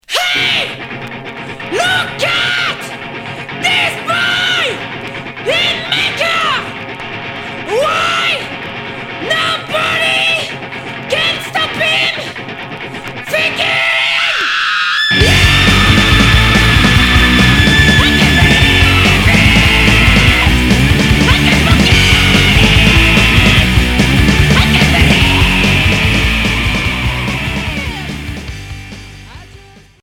Garage noise